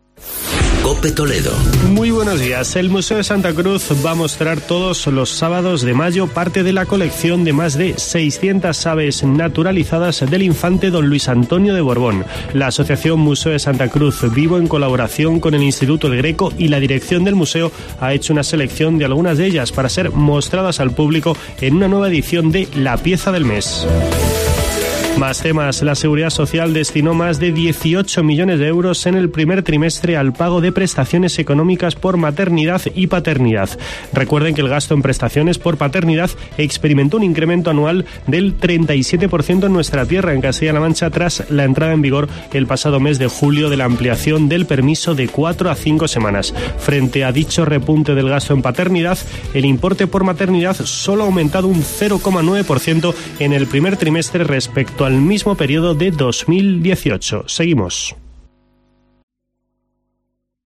Boletín informativo de la Cadena COPE en la provincia de Toledo.